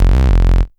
Snare_38.wav